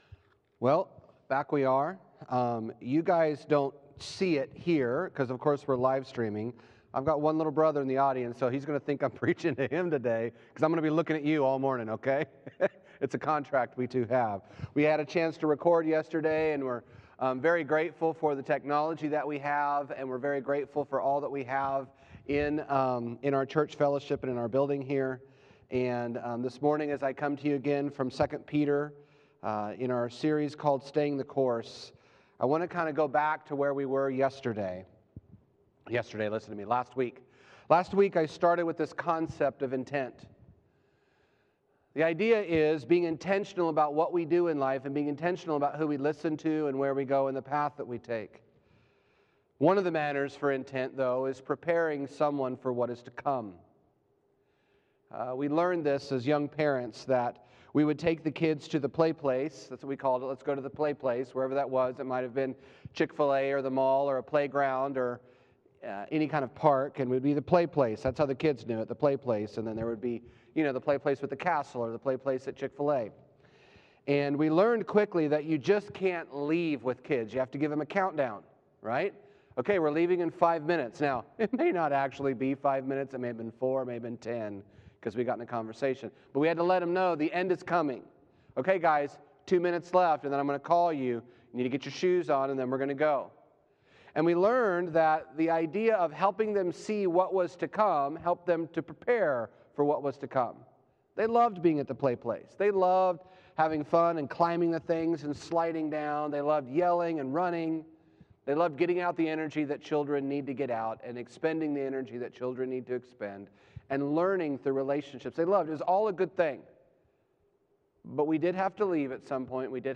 Sermon Series: 2 Peter – Be Patient